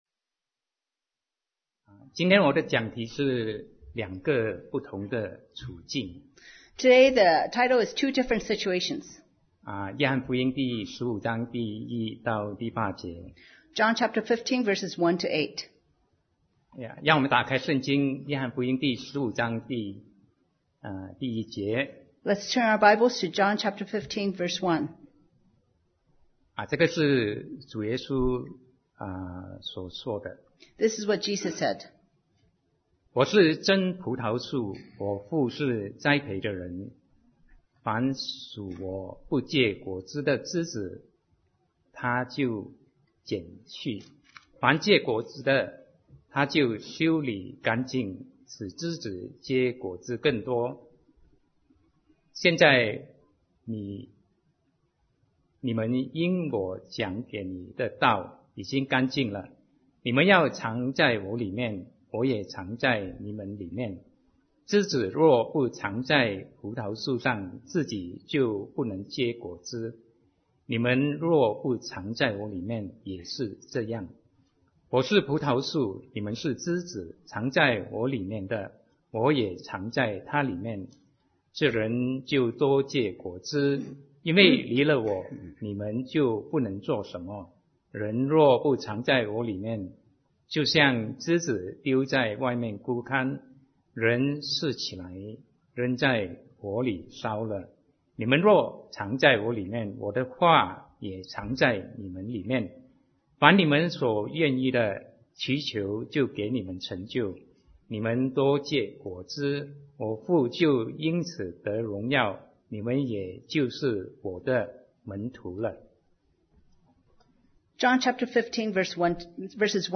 Sermon 2019-07-14 Two Different Situations